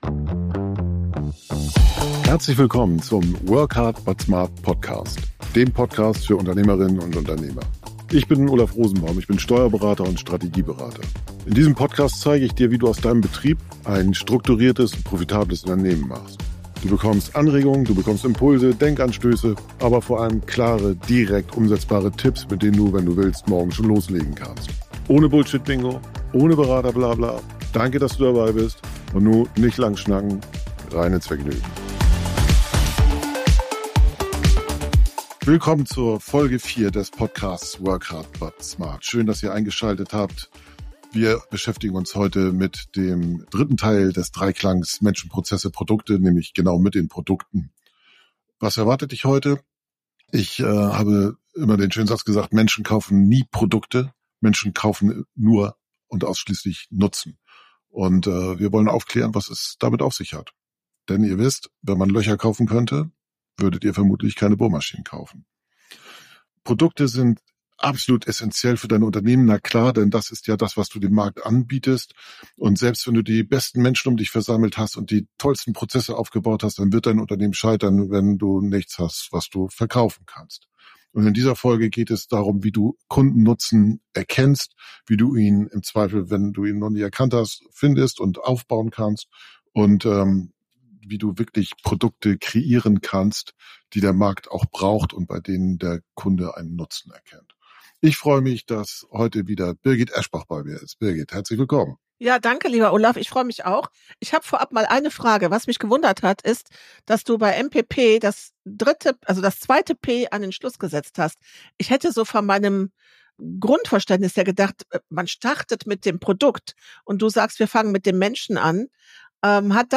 Es geht um Themen wie Wertangebote, emotionale Ansprache im Marketing, Marktpositionierung, die Bedeutung von Feedback-Schleifen und die Notwendigkeit, das Kauferlebnis für Kunden zu verbessern. Die beiden Gesprächspartner geben zahlreiche praktische Tipps, wie Unternehmen ihre Produkte und Dienstleistungen so gestalten können, dass sie den Bedürfnissen ihrer Zielgruppen besser entsprechen.